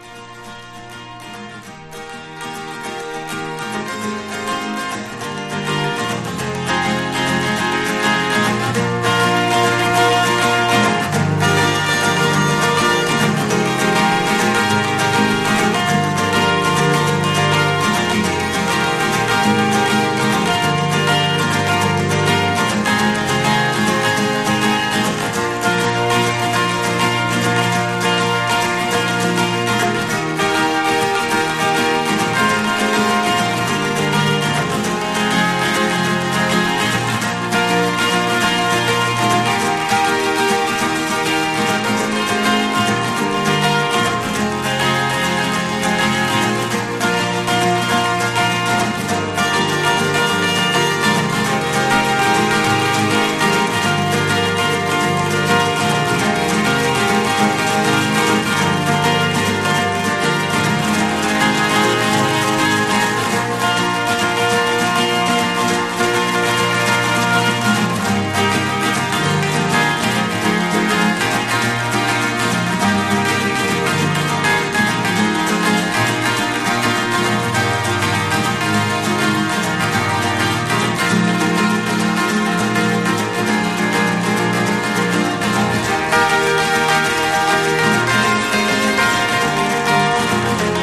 これぞエヴァー・グリーンなネオアコ金字塔！